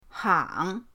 hang3.mp3